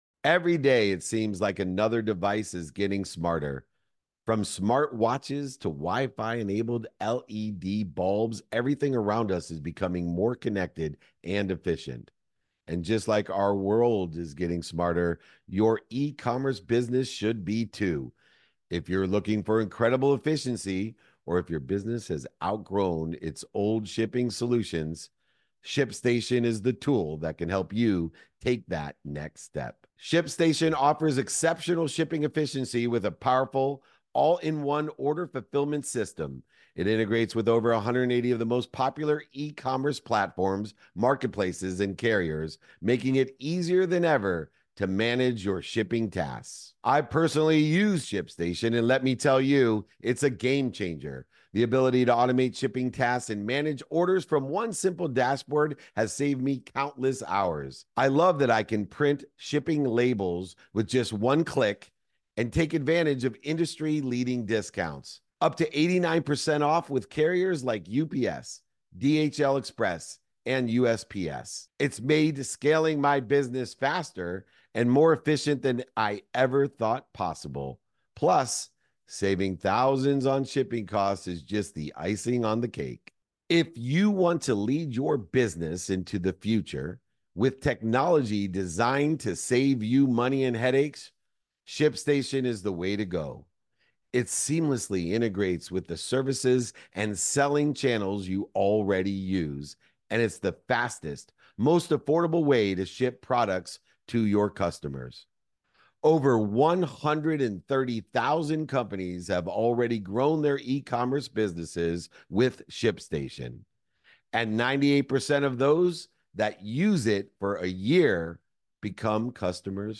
In today’s episode, I’m joined by John Brenkus, the Emmy-winning creator behind the groundbreaking show Sports Science and CEO of Brinks TV. John shares his journey from TV production to launching Brinks TV, a revolutionary platform combining content, commerce, and community.